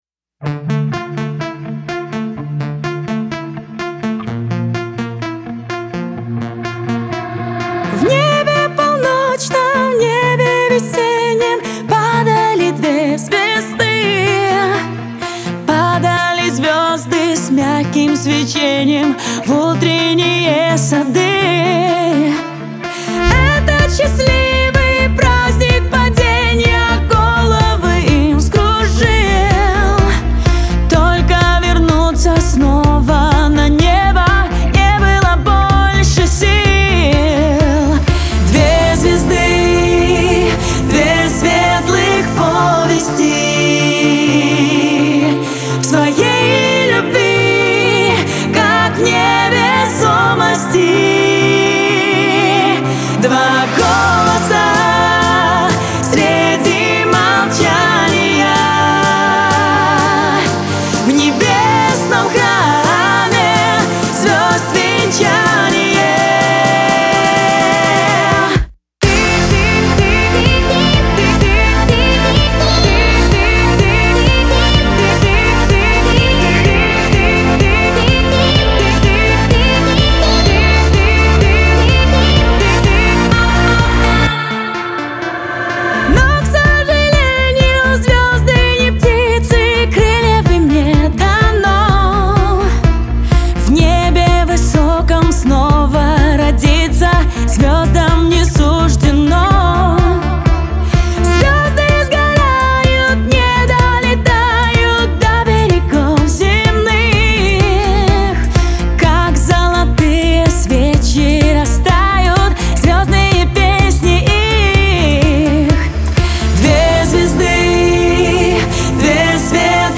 романтичная поп-баллада
наполненная нежностью и меланхолией.